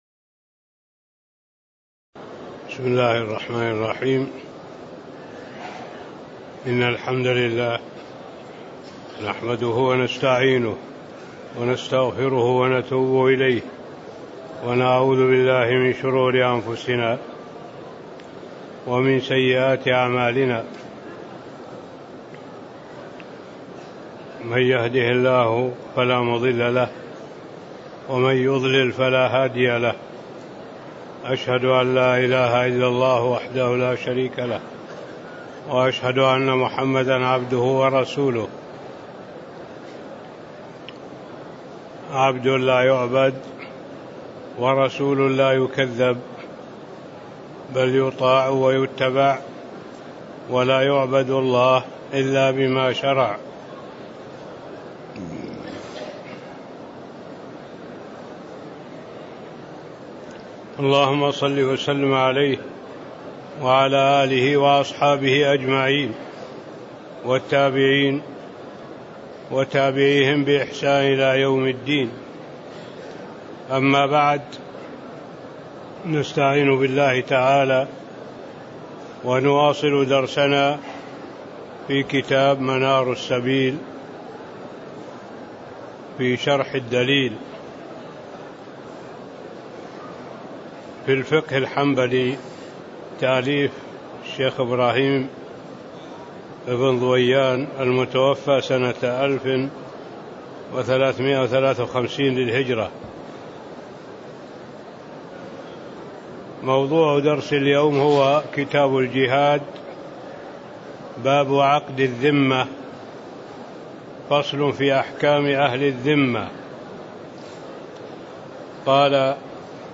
تاريخ النشر ٢٩ ذو الحجة ١٤٣٦ هـ المكان: المسجد النبوي الشيخ